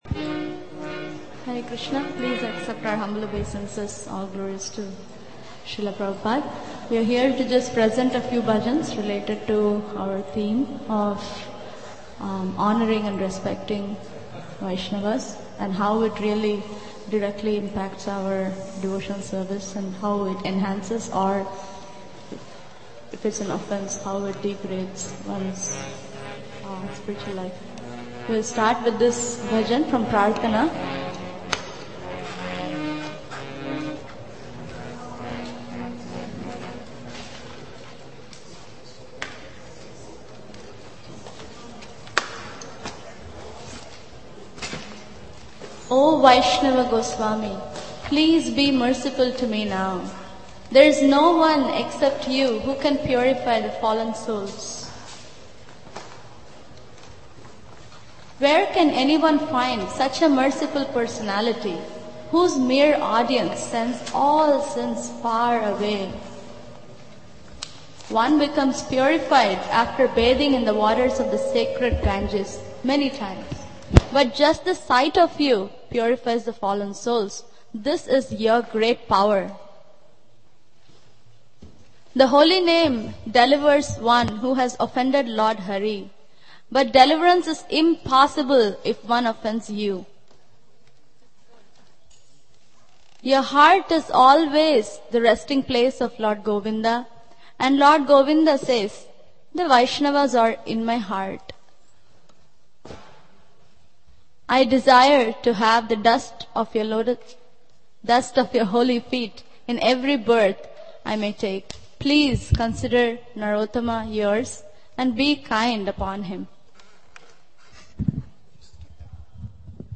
Selected Prayers and Bhajans